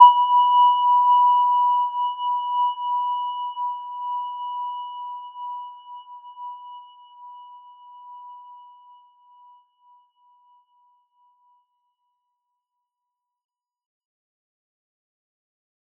Gentle-Metallic-1-B5-mf.wav